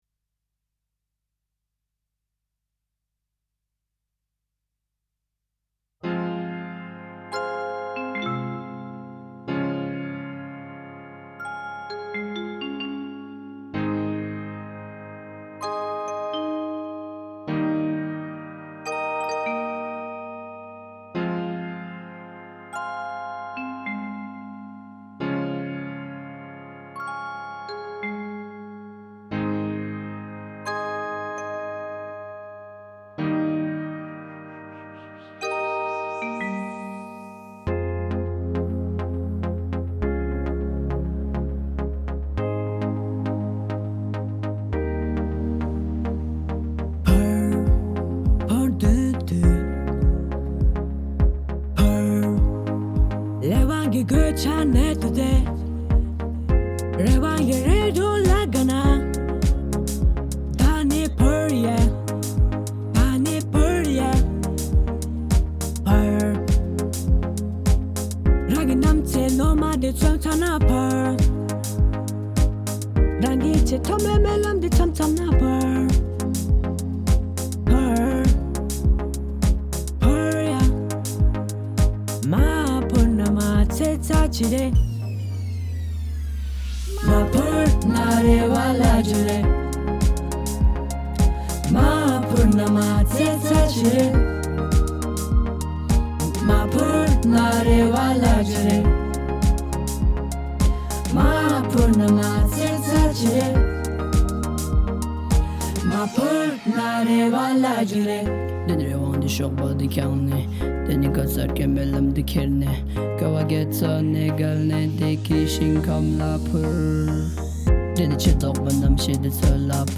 Fly: Tibetan Rap Song
Omnichord